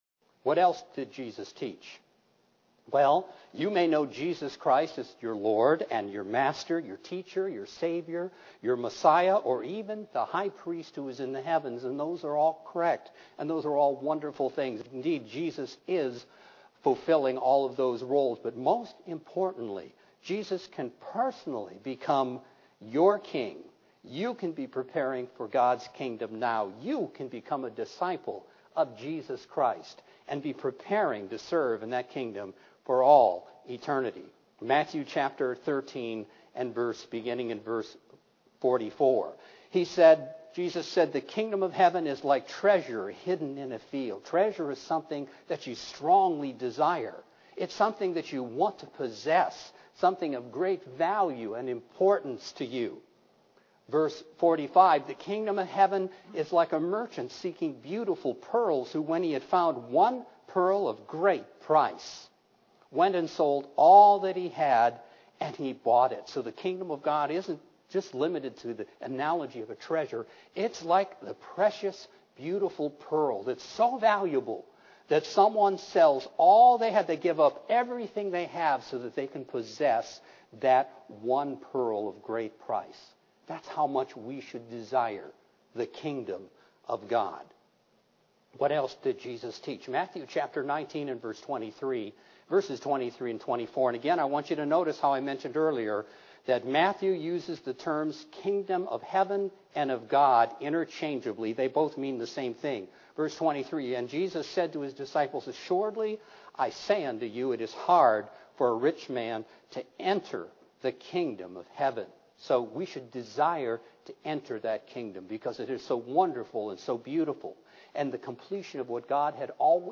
What did Jesus mean that the time is fulfilled and the Kingdom is at hand? Find the answer to these and other questions in this Kingdom of God seminar. (Due to technical difficulties, this segment is already in progress.)